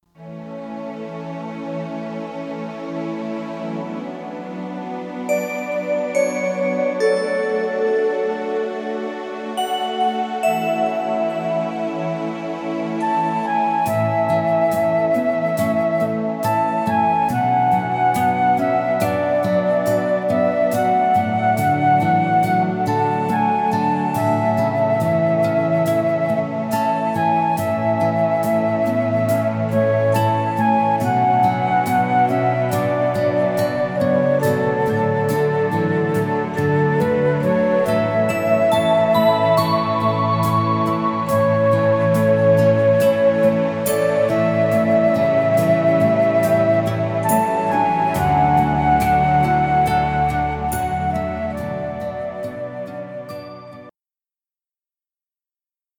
Gefühlvoll arrangierte Instrumentalmusik zum Planschen …